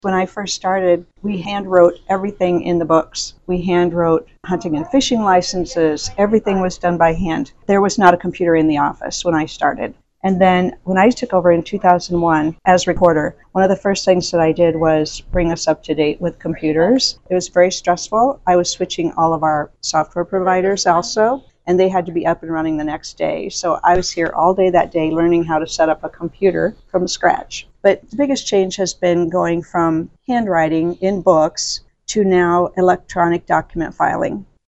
Wilkinson recently spoke with RadioOnTheGo News about her time working as the county recorder and what her office does on a daily basis.